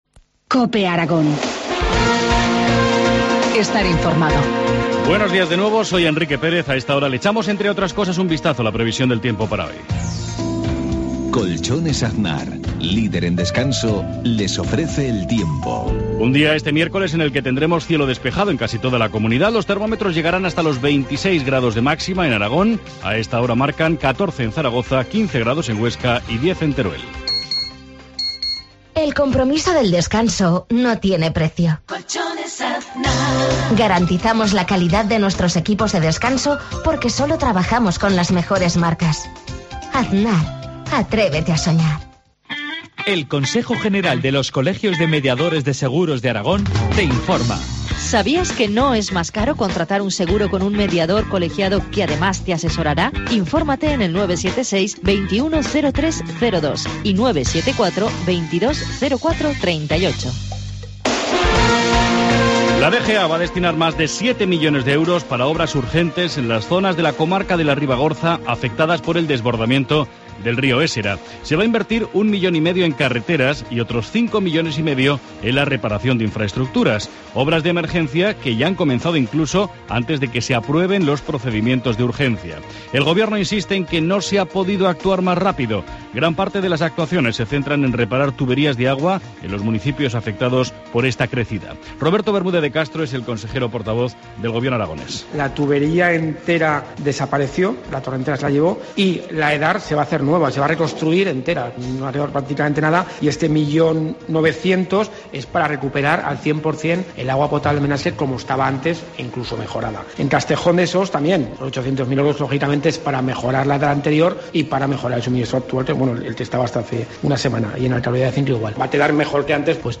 Informativo matinal, miércoles 26 de junio, 7.53 horas